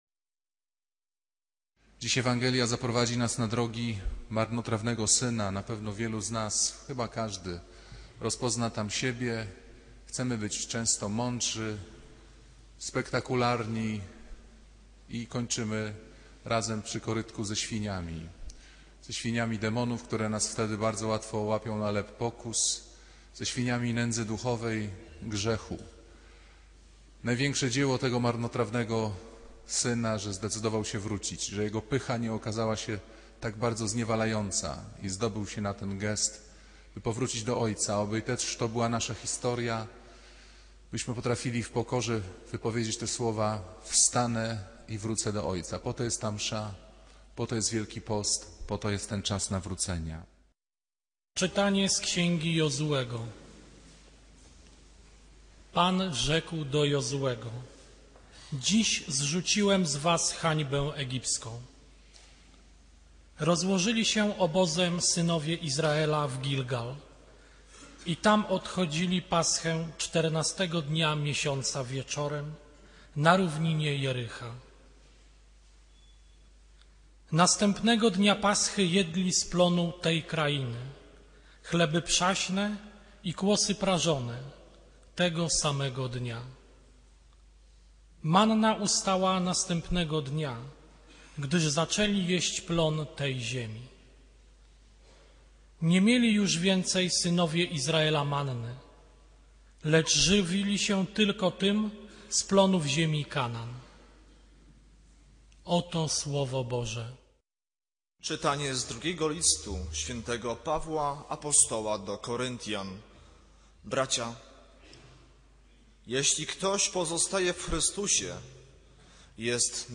Kazania księdza Pawlukiewicza o upadku i powrocie.